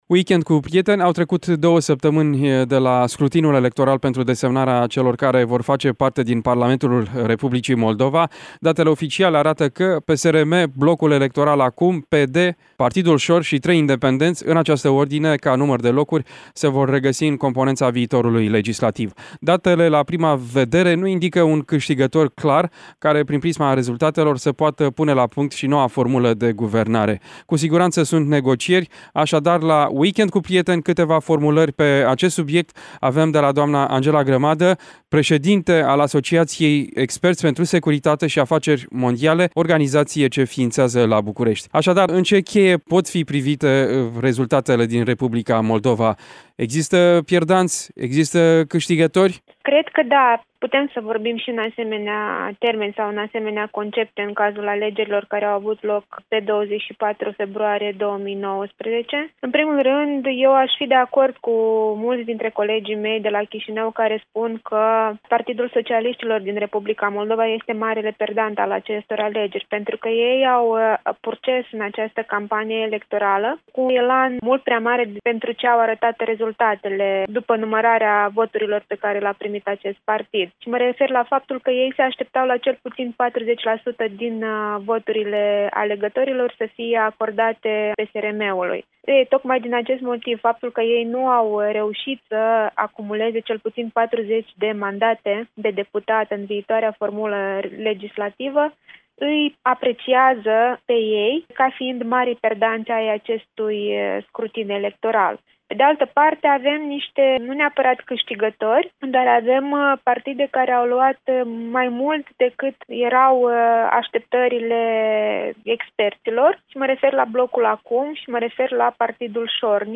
Scenarii postelectorale în Republica Moldova. Interviu